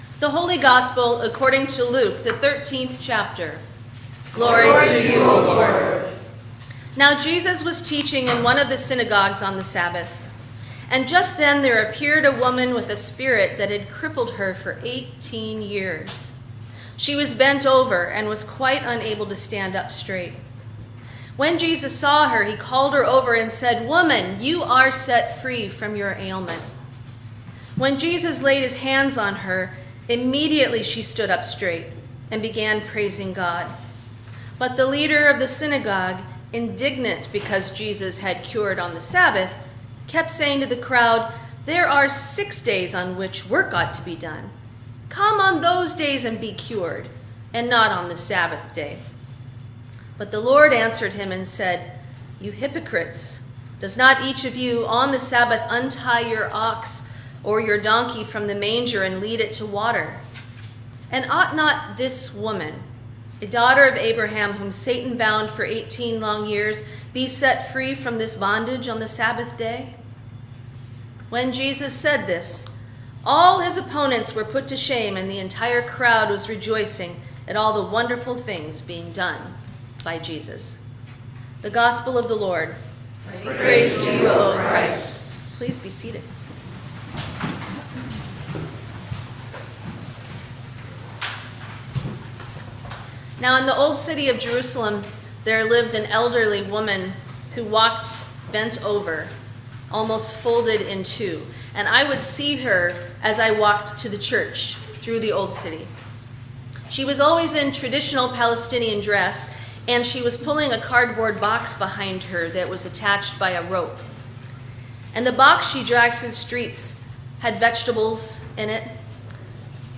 Sermon for the Eleventh Sunday after Pentecost 2025